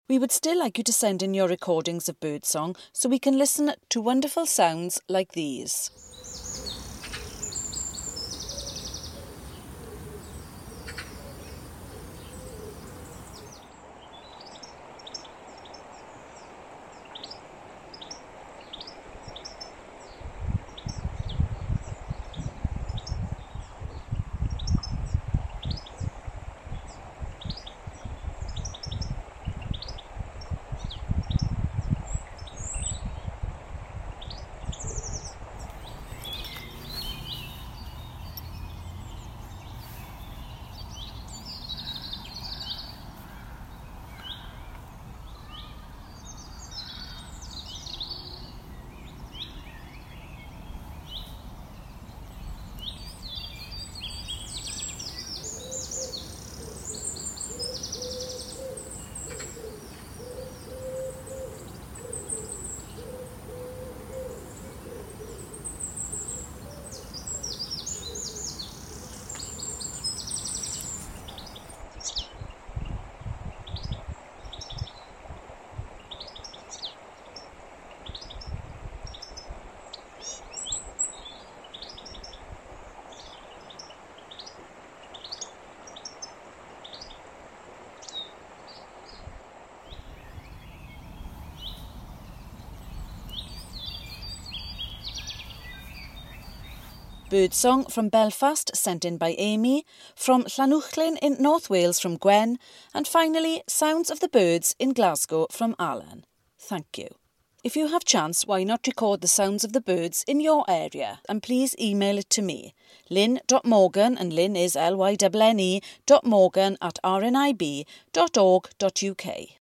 Bird Song